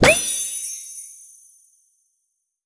magic_pop_open_03.wav